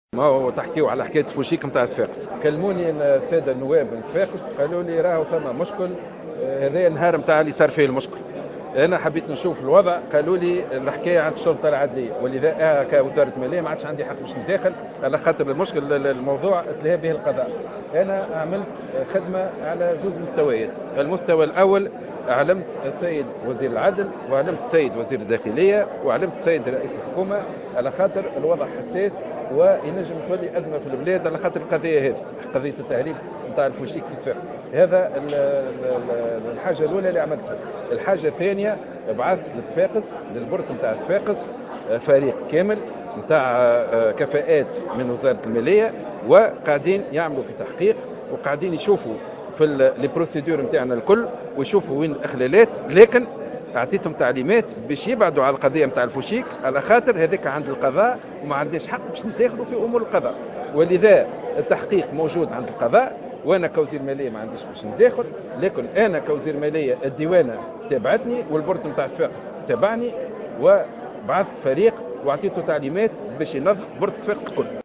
أكد وزير المالية سليم شاكر في تصريح لجوهرة "اف ام" اليوم الجمعة 3 أفريل 2015 أن وزارة المالية لاعلاقة لها بقضية تهريب الألعاب النارية الفوشيك التي تم الكشف عن تفاصيلها مؤخرا موضحا أن هذه القضية أصبحت من مهامالقضاء وحده ولايمكن لوزير المالية ان يتدخل في عمل القضاء وفق قوله.